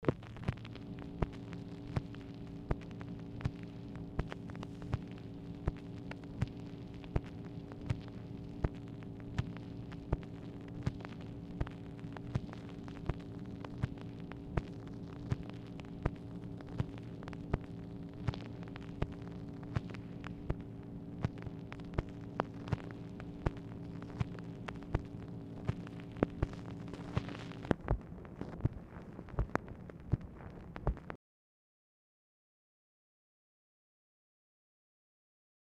Telephone conversation # 5042, sound recording, MACHINE NOISE, 8/19/1964, time unknown | Discover LBJ
Format Dictation belt